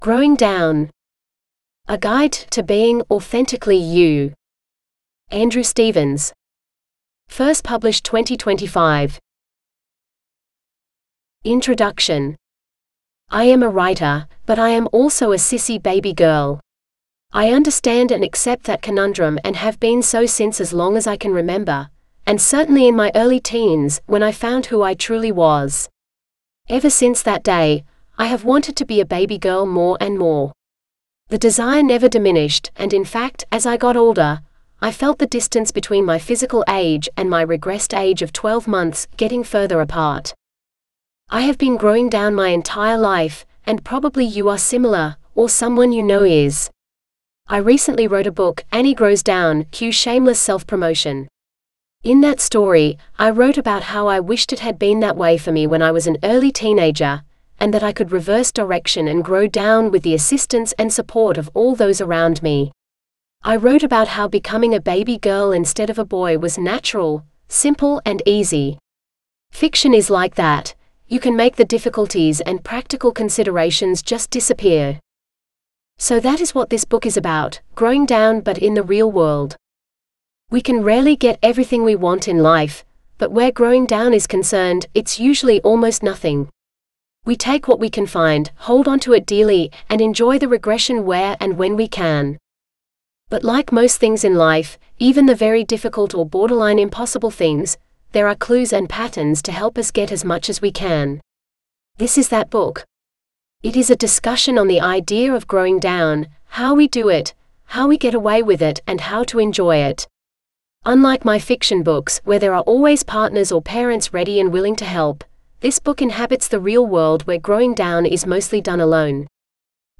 Growing Down Guide (AUDIOBOOK – female voice) $5.75